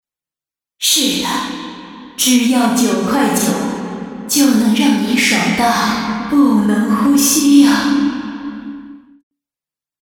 女声
英雄联盟角色模仿-14风女